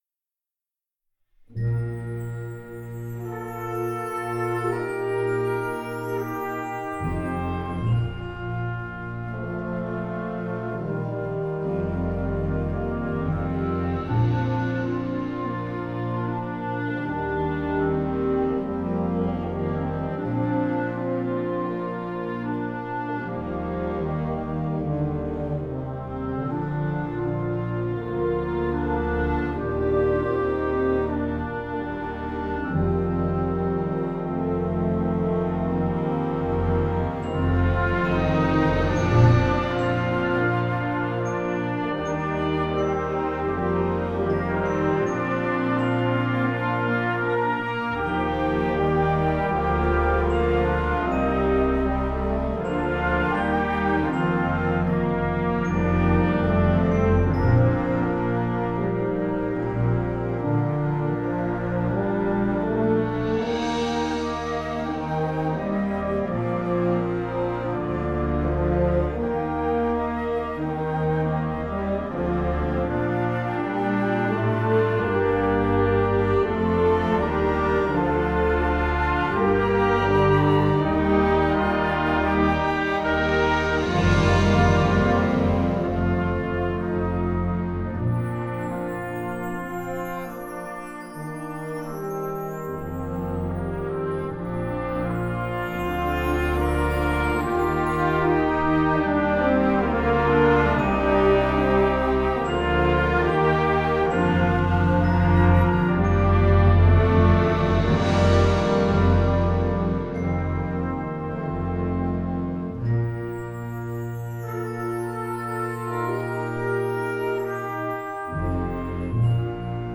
Gattung: Konzertwerk für Jugendblasorchester
Besetzung: Blasorchester
sehr leicht spielbares, sanftes Stück